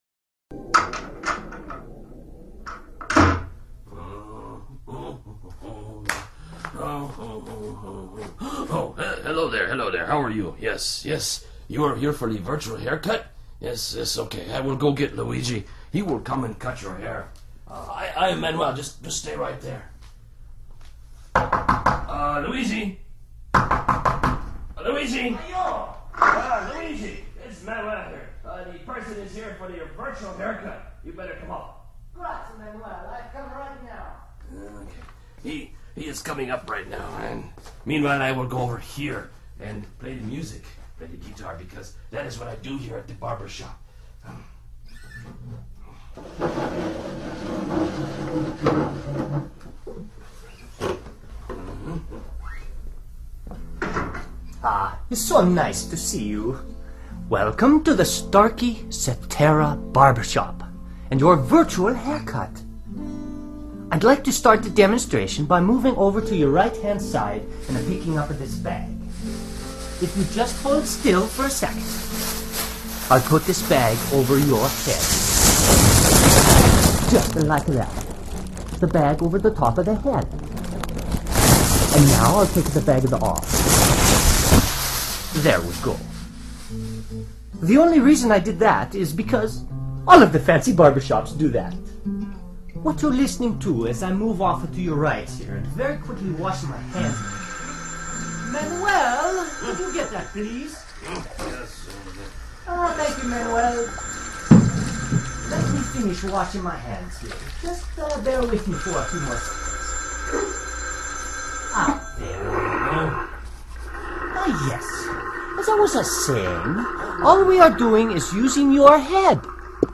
خدع صوتية
فلا يمكن للأذن الواحد تحديد مصدر الصوت بينما تتمكن الأذنين من فعل ذلك. هذا الملف الصوتي يكشف لنا هذه الحقيقة ولسماع ذلك استعمل سماعة ذات رأسين (headphone) وستلاحظ مصادر الصوت من أماكن مختلفة وكأنك جالس في محل حلاقة.